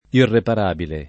[ irrepar # bile ]